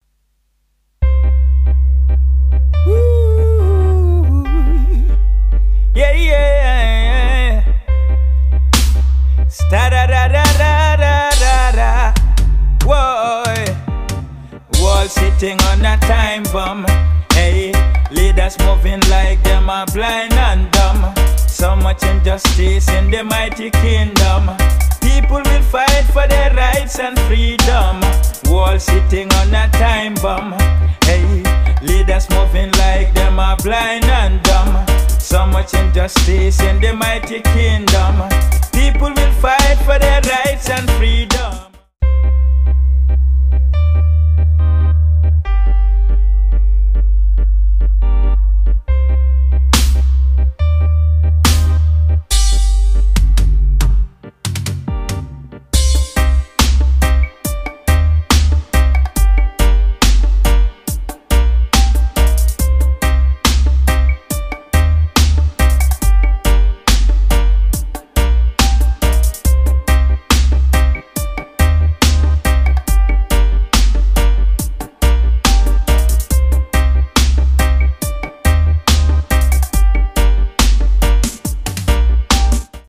mixing and master